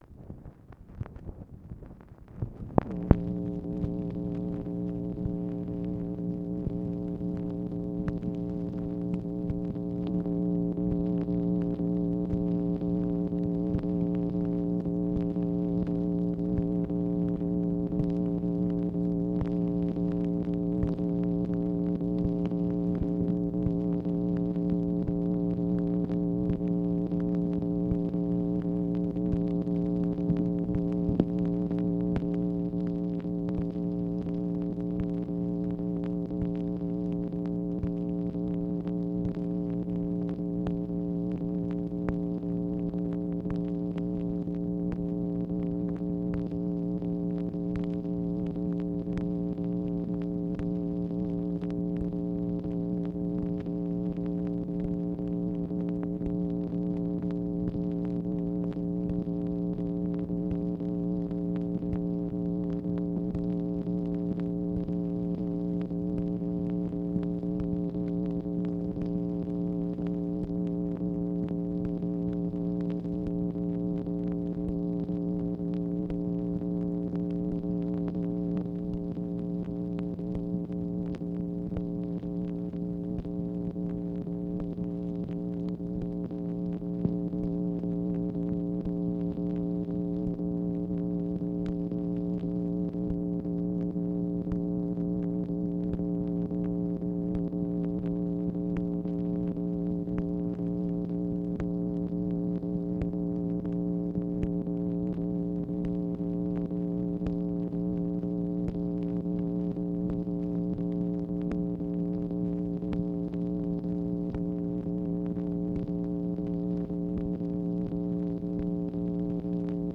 MACHINE NOISE, August 6, 1965
Secret White House Tapes | Lyndon B. Johnson Presidency